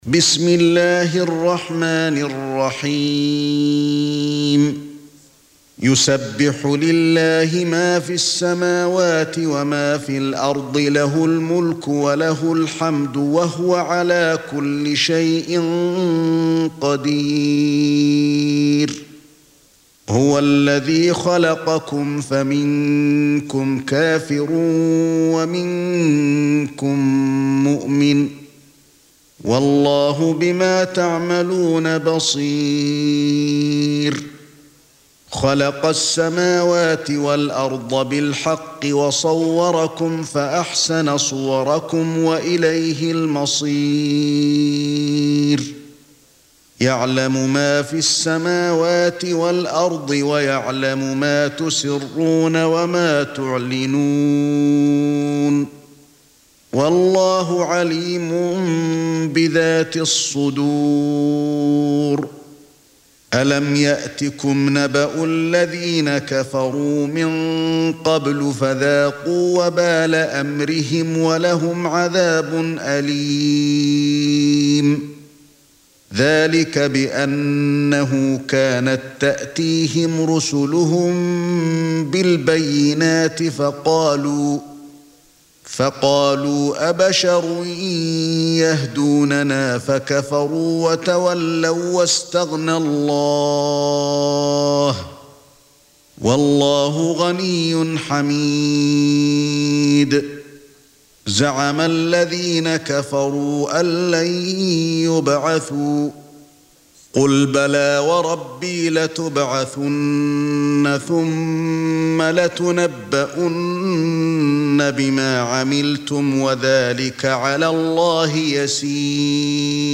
Audio Quran Tarteel Recitation
حفص عن عاصم Hafs for Assem
Recitations with Sheikh Ali Alhuthaifi
Surah Sequence تتابع السورة Download Surah حمّل السورة Reciting Murattalah Audio for 64. Surah At-Tagh�bun سورة التغابن N.B *Surah Includes Al-Basmalah Reciters Sequents تتابع التلاوات Reciters Repeats تكرار التلاوات